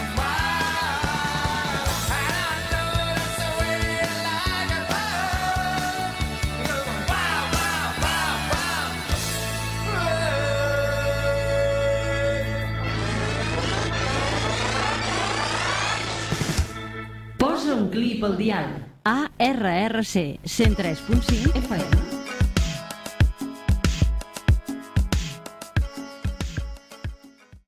Música i indicatiu de l'emissora